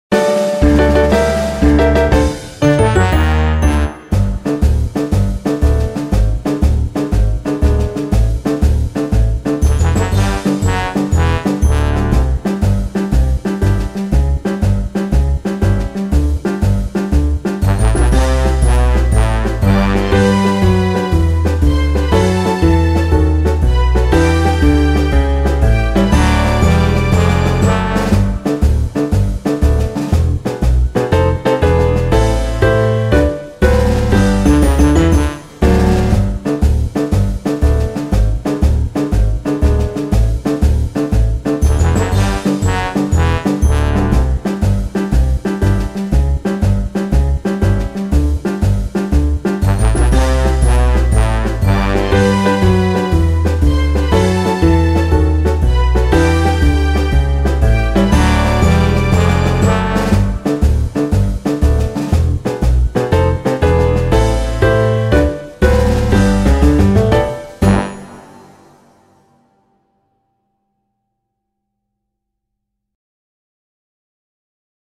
De cada una de ellas hay un audio con el violín tocando y el piano y la batería acompañando y el segundo audio con la segunda voz, el piano y la batería.